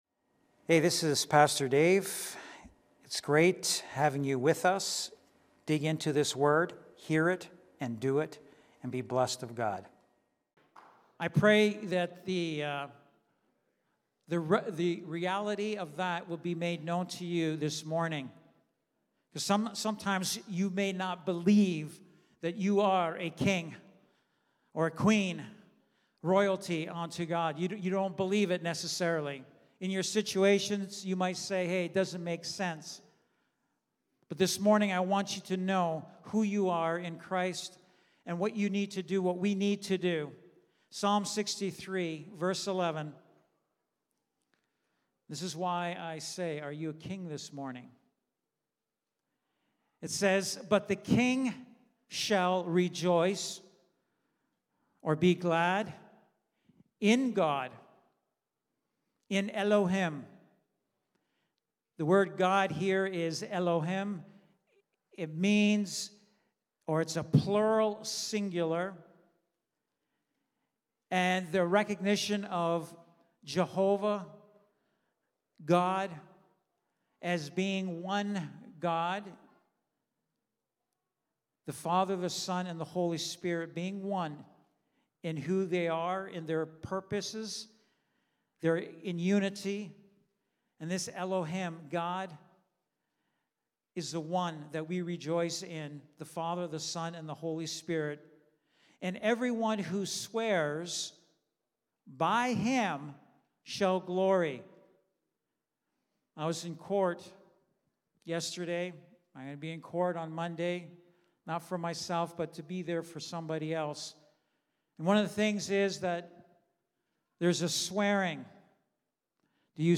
Sunday Morning Service
Lighthouse Niagara Sermons